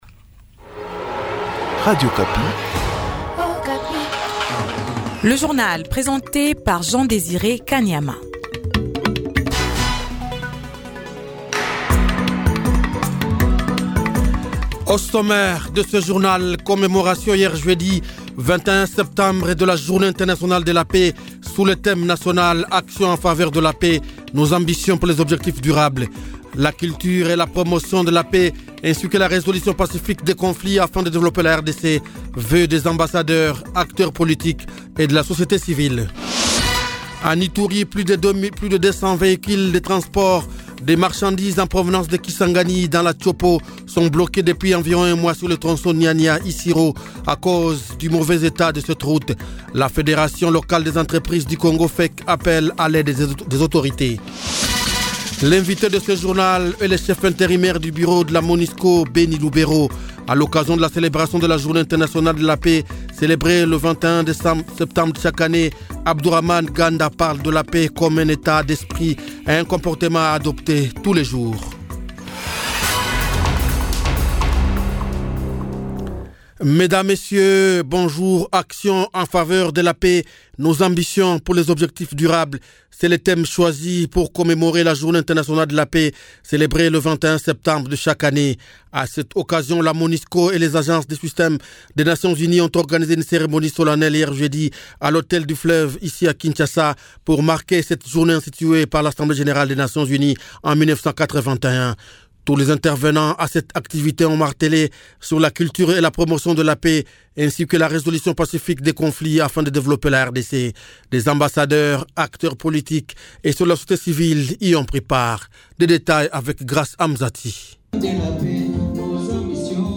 Journal Francais